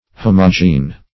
Homogene \Ho"mo*gene\, a.